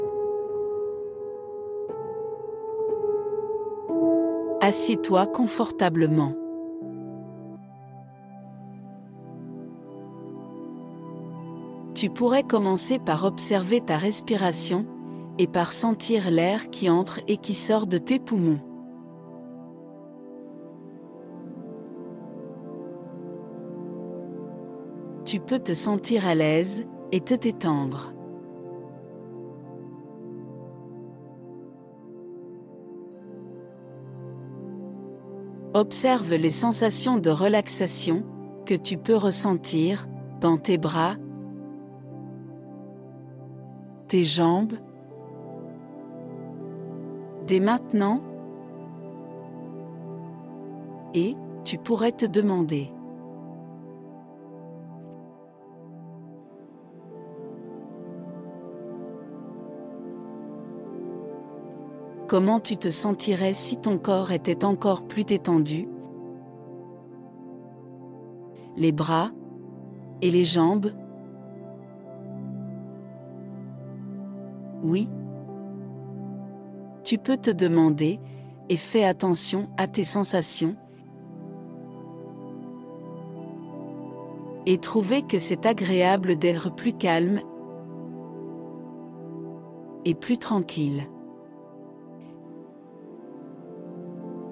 • J’ai mis ici deux extraits de cette méditation.